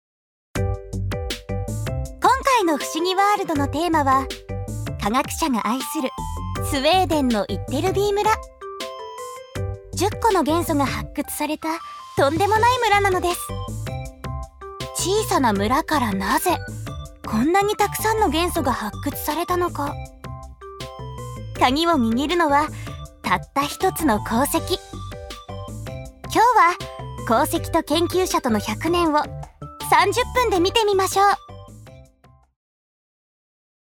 ジュニア：女性
ナレーション１